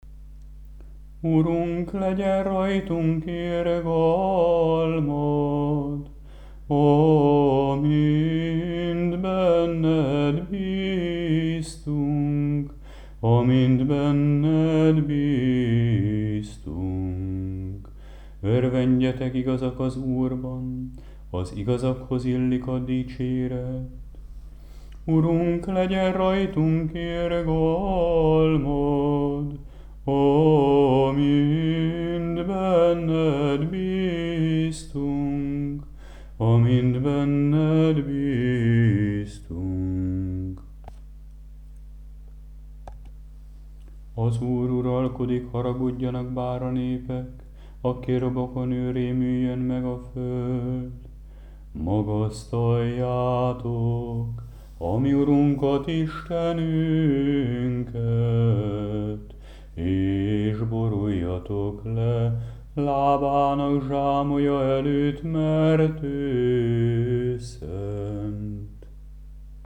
10. Prokimen 1. és 7. hang, föltám. és Szent Kereszt.mp3